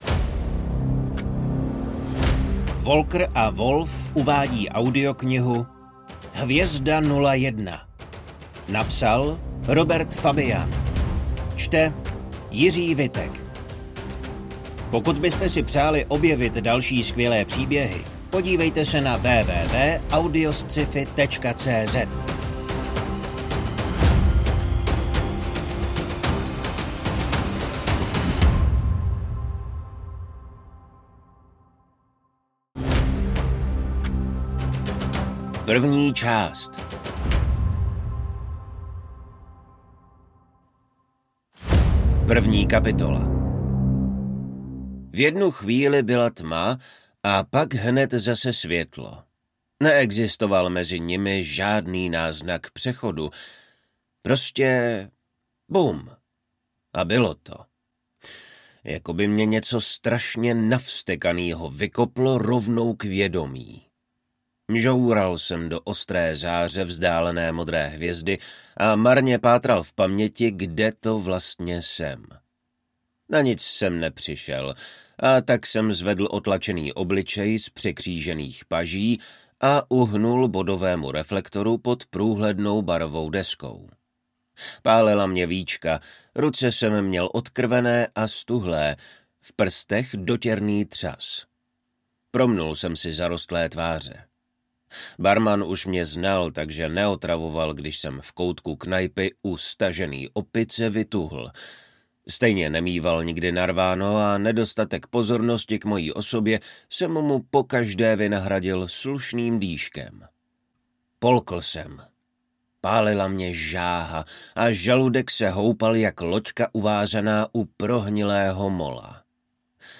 AudioKniha ke stažení, 17 x mp3, délka 10 hod. 2 min., velikost 551,1 MB, česky